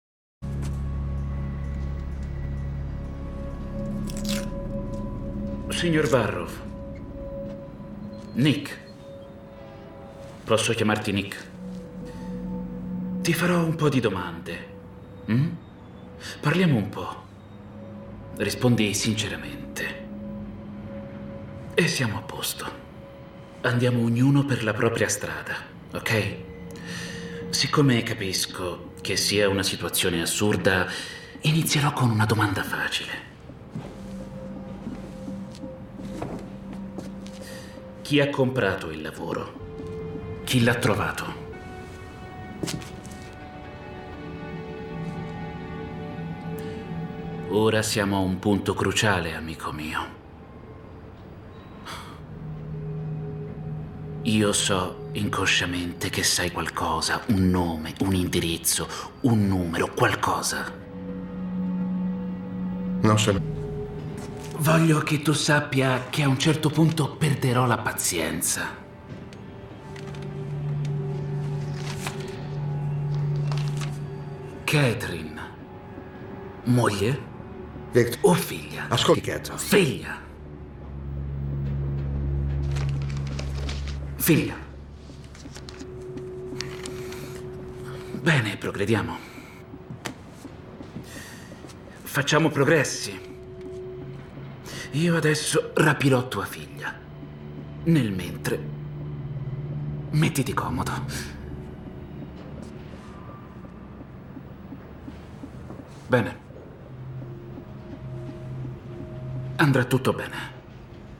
nel film "Tempo limite", in cui doppia Jordi Mollà.